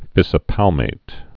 (fĭsə-pălmāt)